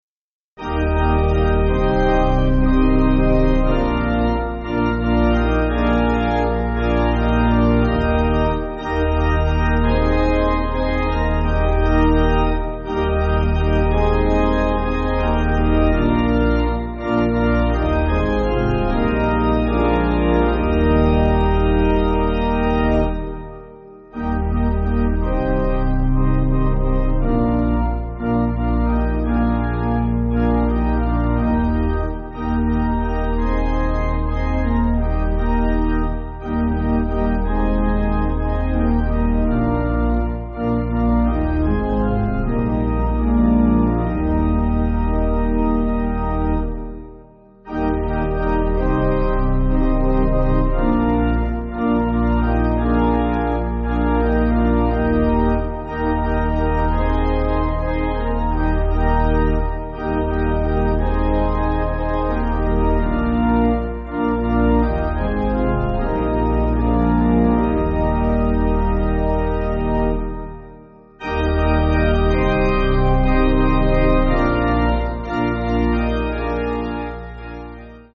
Organ
(CM)   4/Eb